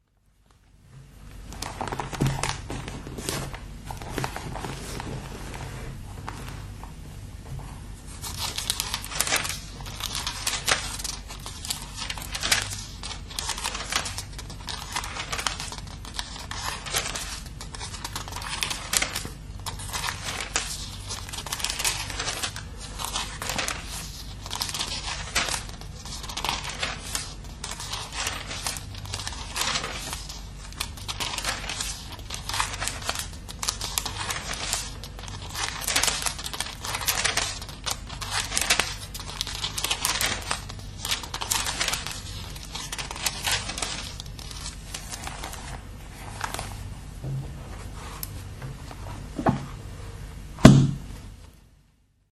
描述：翻开教会在1942年给我父亲的《圣经》（荷兰语译本）中的数字书页。